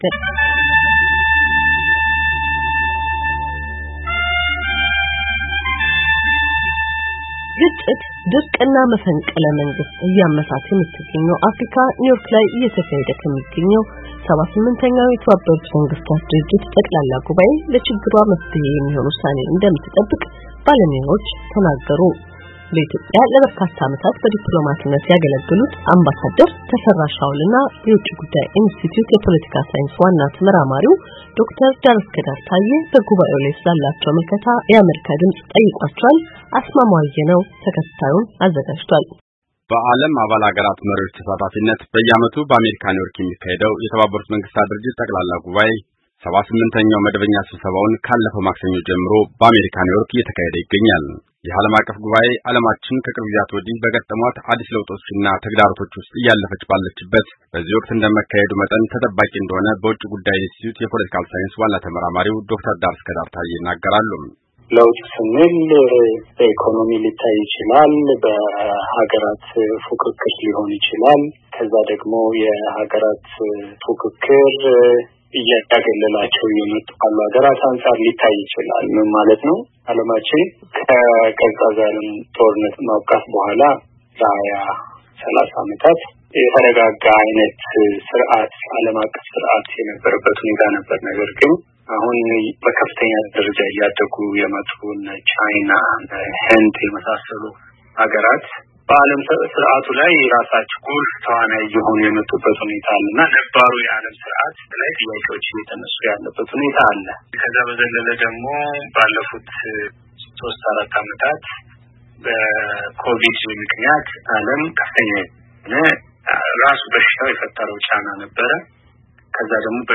በጉባኤው ላይ ስላላቸው ምልከታ የአሜሪካ ድምፅ ጠይቋቸዋል።